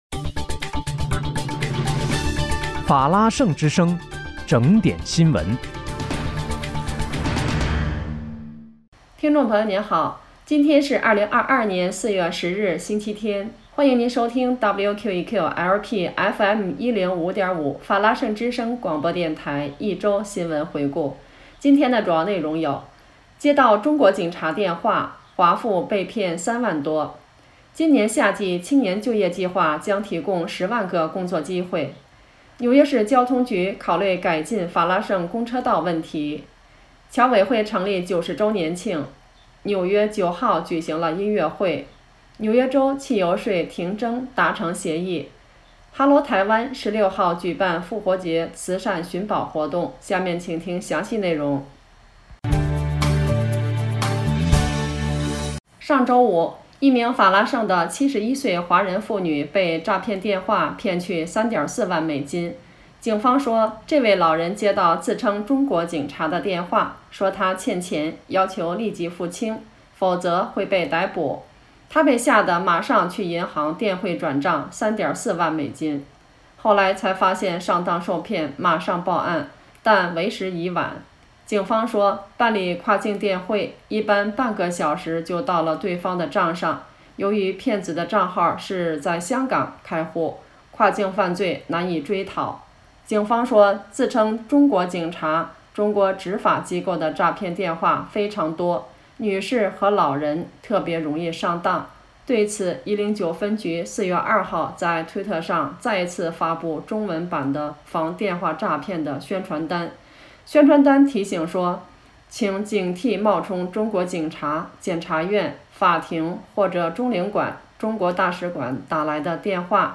4月10日（星期日）一周新闻回顾